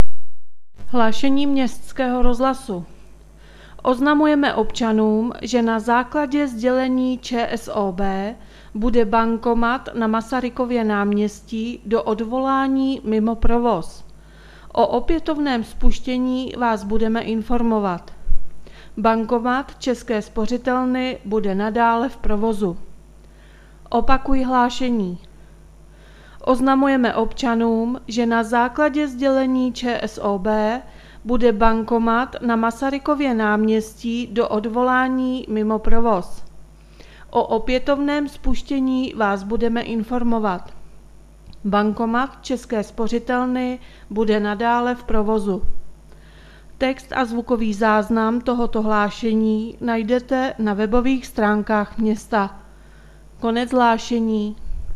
Hlášení městského rozhlasu 23.7.2020